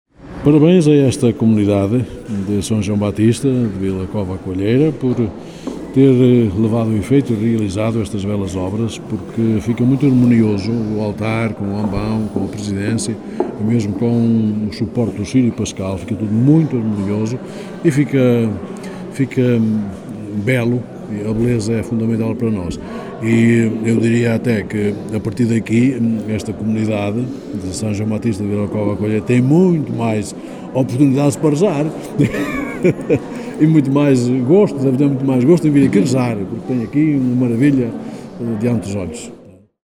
D. António Couto, Bispo de Lamego em declarações à Alive FM, deu os parabéns à comunidade de São João Baptista de Vila Cova à Coelheira pela requalificação desta obra, “Esta comunidade tem muito mais oportunidades para rezar…”.